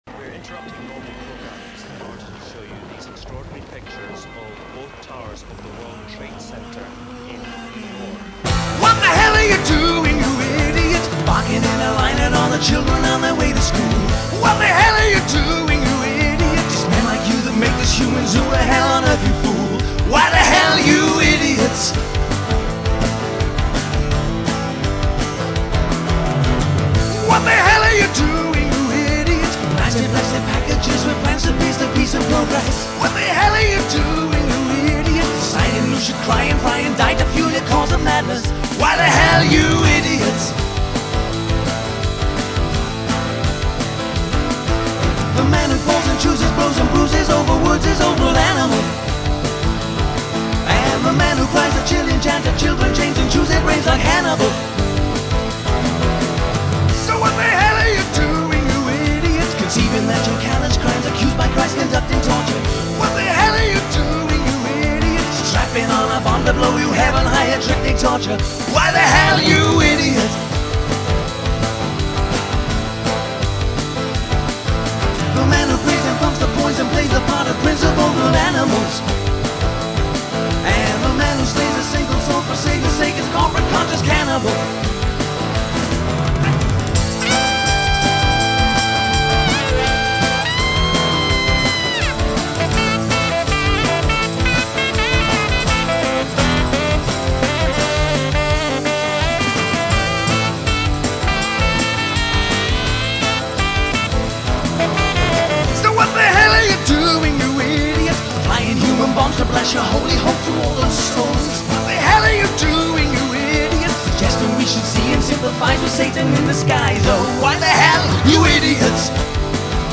Recorded at Stoneye Studios, December 2001
Electronic Drum Pads
Saxophone